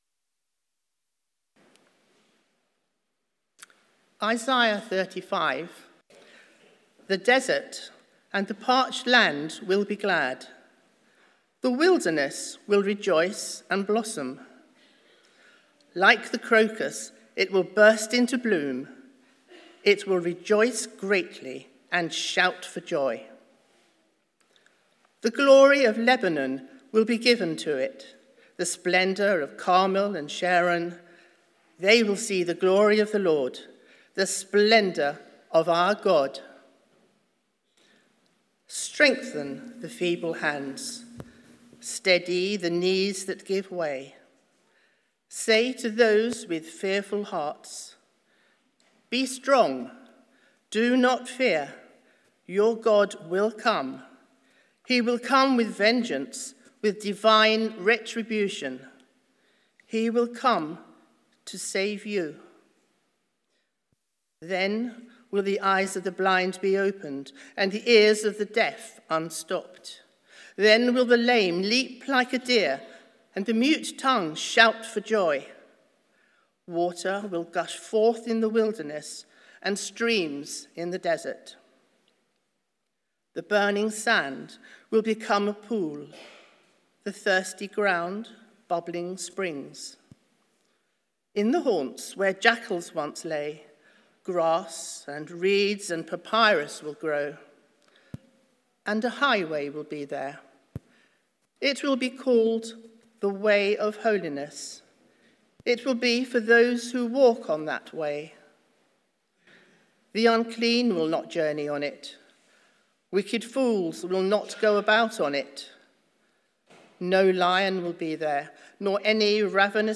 One-Off Sermons Passage: Isaiah 35:1-10 Service Type: Sunday Morning Sermon Transcript « Sharing with those in need Whose word will you trust?